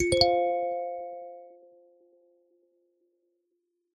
Nada notifikasi iPhone Chord
Keterangan: Nada dering Chord iPhone ringtone...
nada-notifikasi-iphone-chord-id-www_tiengdong_com.mp3